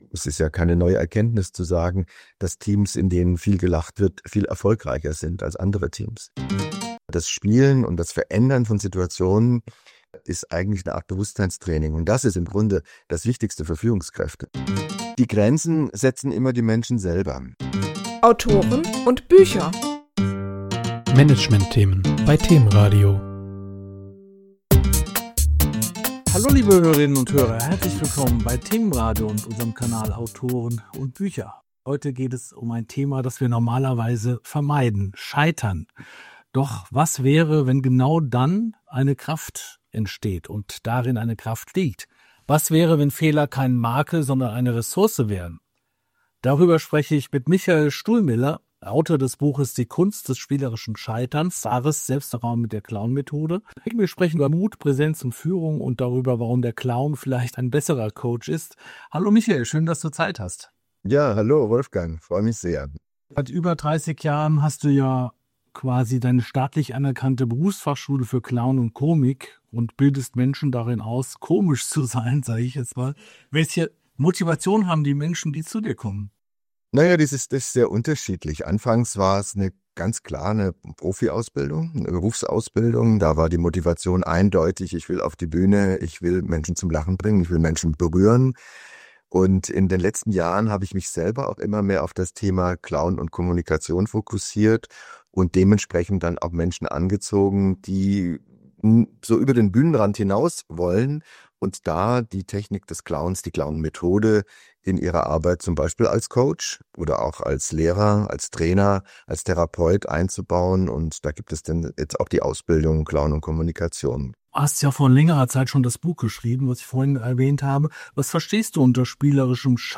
In dieser Folge von Themen-Radio sprechen wir mit ihm über einen radikal anderen Zugang: Scheitern als Spielraum.